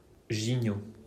Gignod (French: [ʒiɲo]
Fr-Gignod.mp3